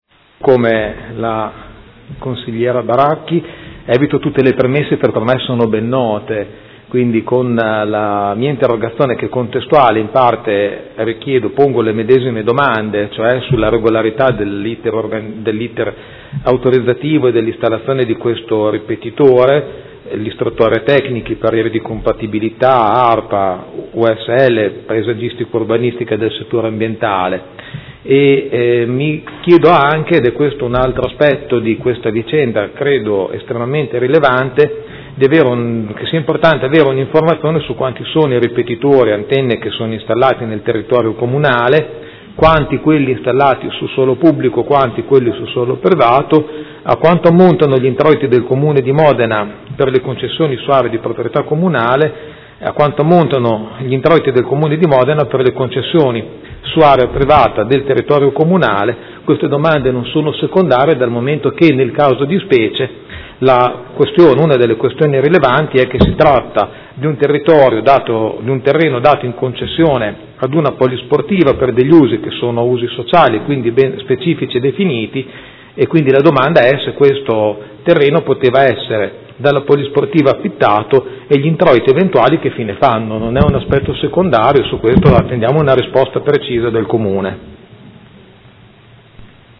Seduta del 14/04/2016 Interrogazione del Consigliere Pellacani (F.I.) avente per oggetto: Nuovo ripetitore telecomunicazioni in Via Scaglia ovest installato ad insaputa degli abitanti della zona